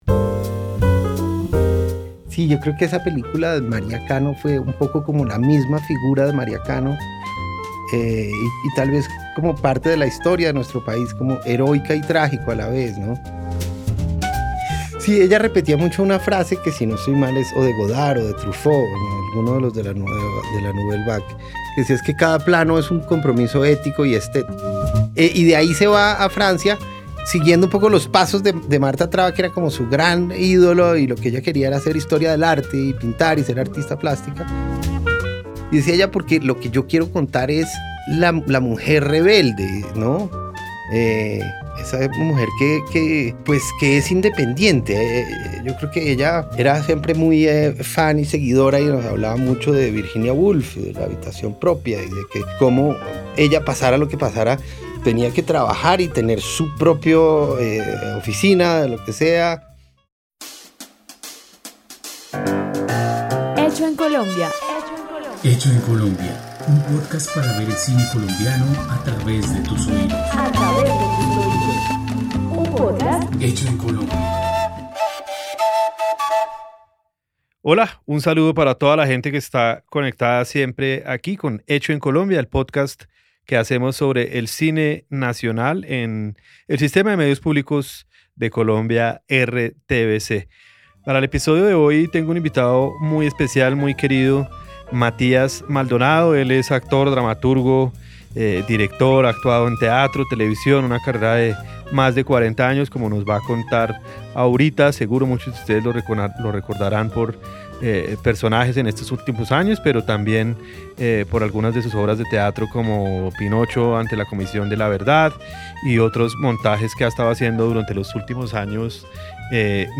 Una conversación íntima